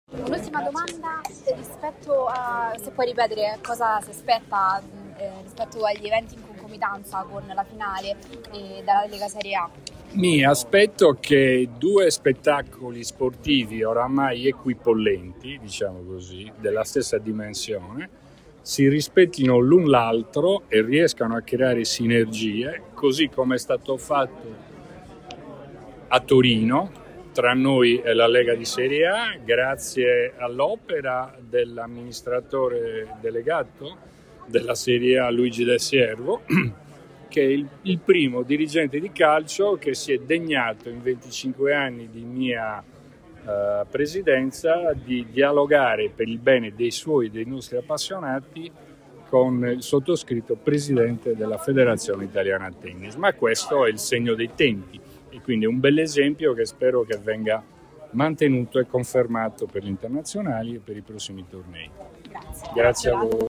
Durante la presentazione, il Presidente della FITP Angelo Binaghi ha affrontato uno dei temi più caldi per l’ordine pubblico e la passione sportiva della Capitale: la concomitanza, prevista per il 17 maggio, tra la finale del torneo e il derby calcistico tra Roma e Lazio.